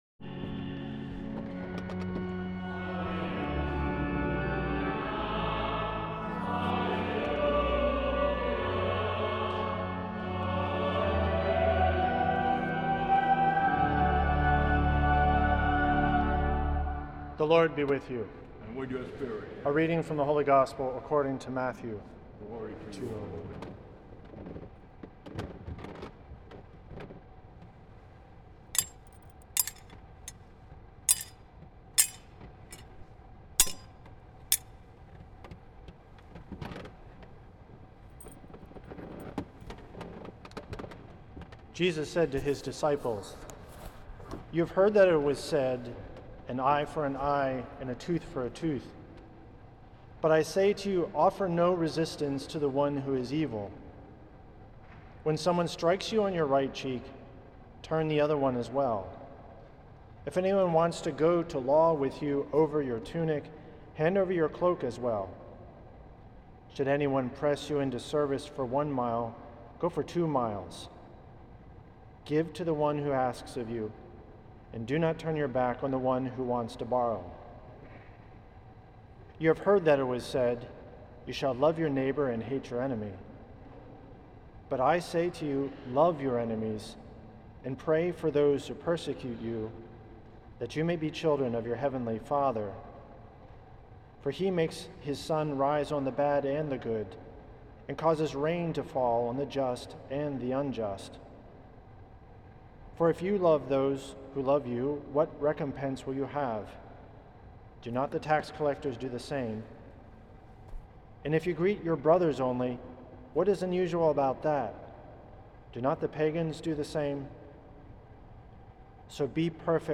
for the 7th Sunday in Ordinary Time, at St. Patrick’s Old Cathedral in NYC.